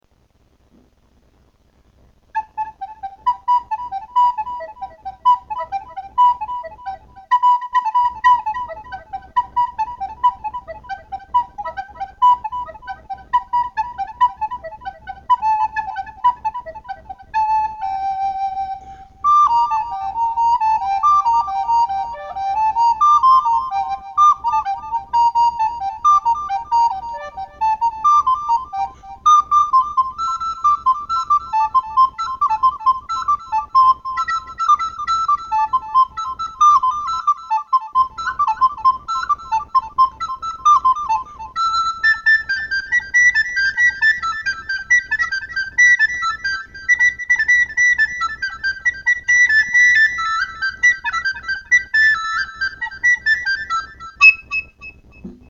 Woman's Game Song (Maidu), soprano recorder